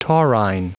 Prononciation audio / Fichier audio de TAURINE en anglais
Prononciation du mot taurine en anglais (fichier audio)